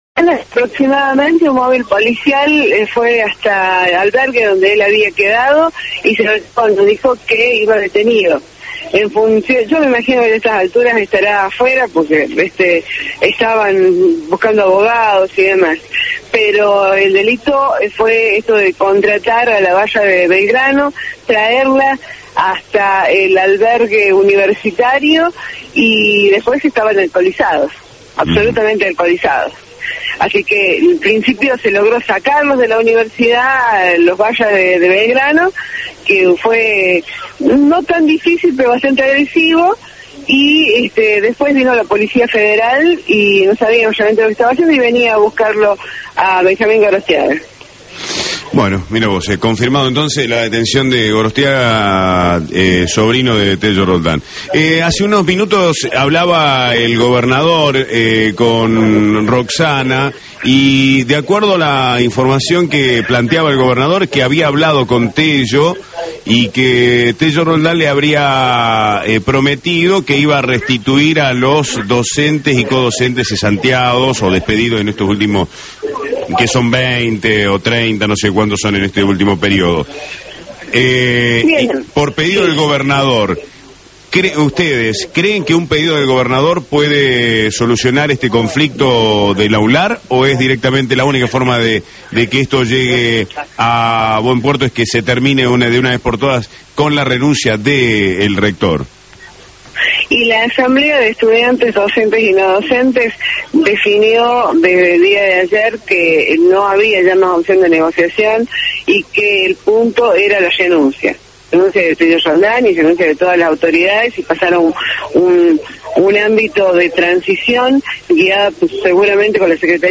en Radio La Red